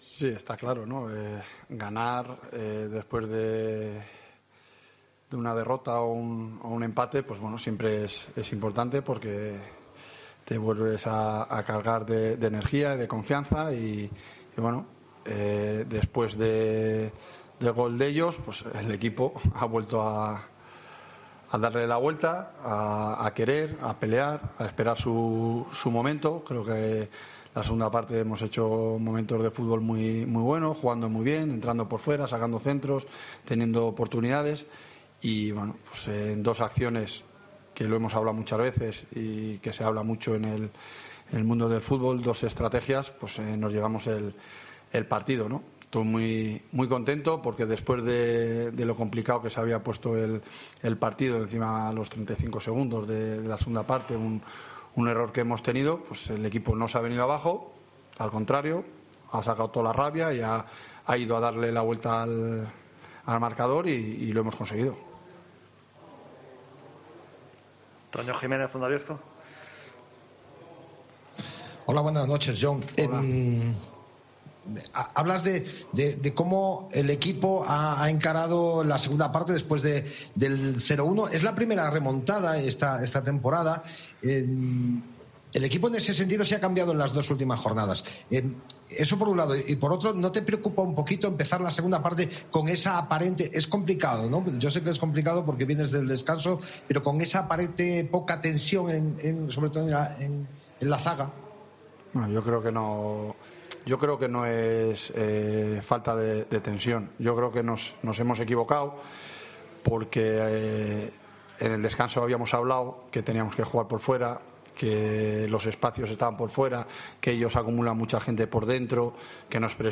Lee y escucha aquí las palabras del entrenador de la Deportiva Ponferradina tras la victoria 2-1 ante el Real Zaragoza